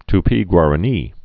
(t-pēgwär-ə-nē, tpē-)